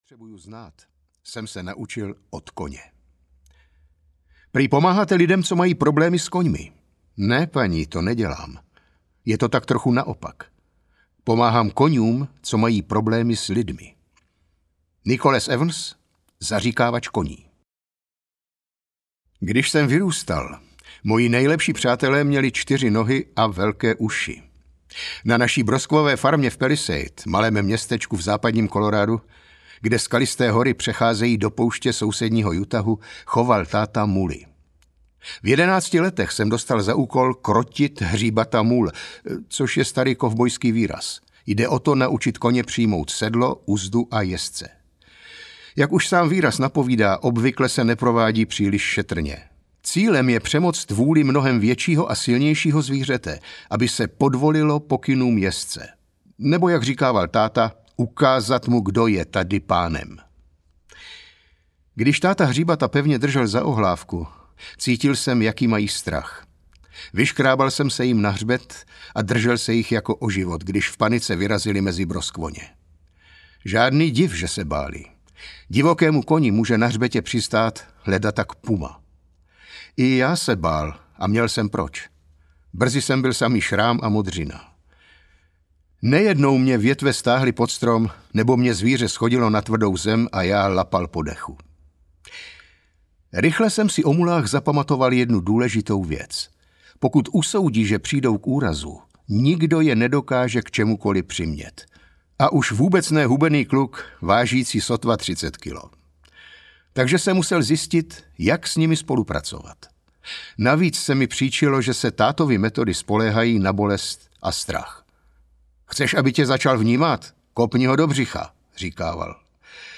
Moudrost koní audiokniha
Ukázka z knihy
moudrost-koni-audiokniha